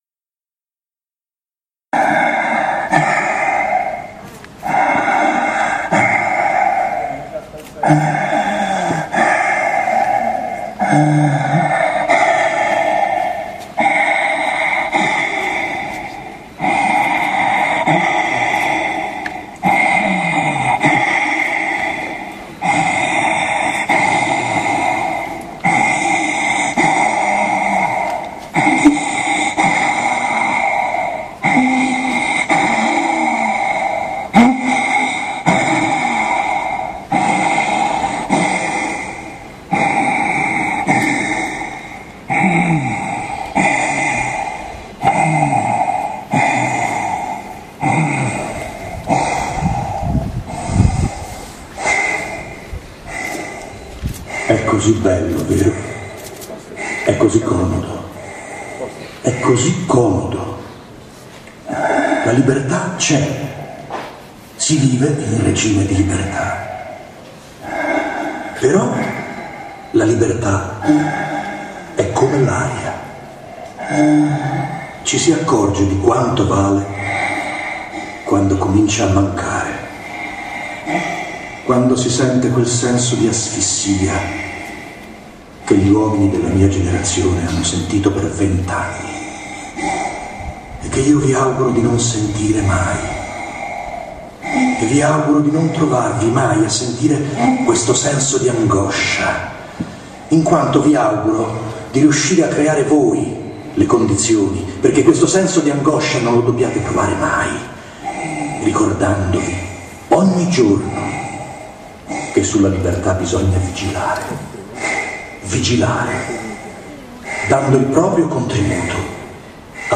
Manifestazione organizzata dal Comitato Bonino Presidente, 8 aprile 2013 a Roma  presso la Domus Talenti.